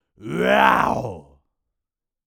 Male_Medium_Roar_01.wav